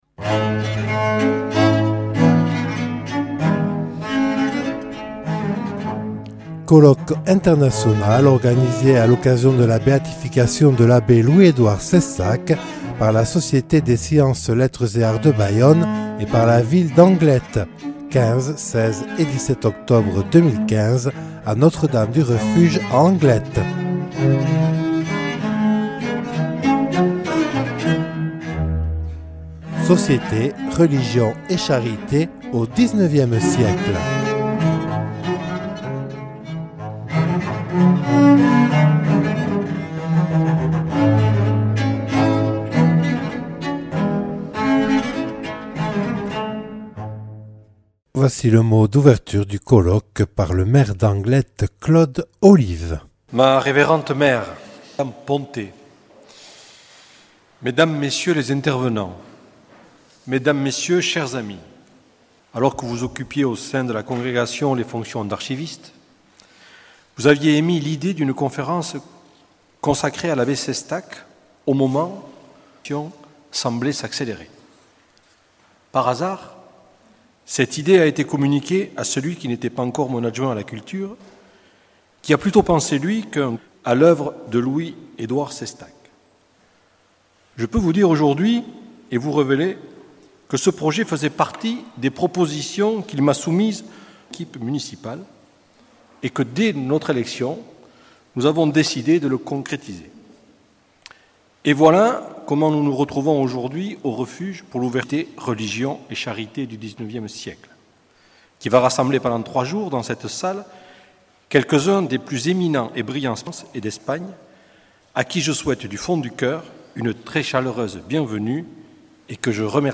Ouverture du colloque par Claude Olive, maire d’Anglet.